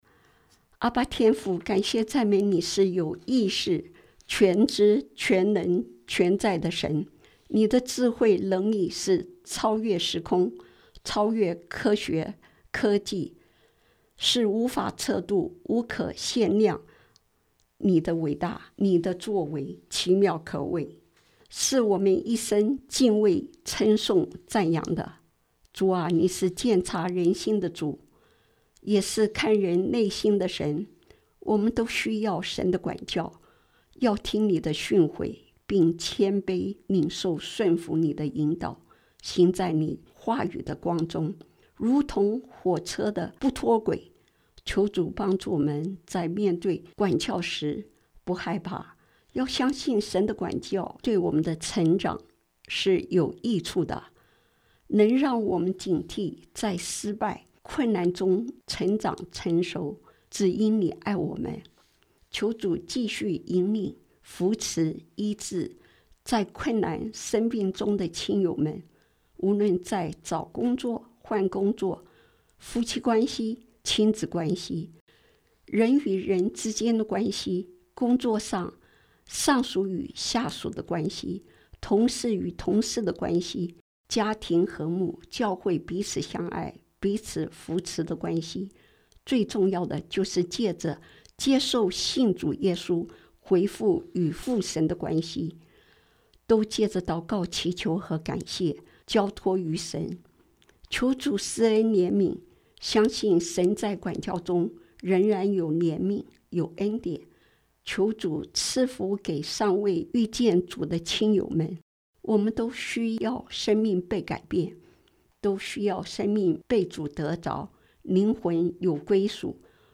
祷告词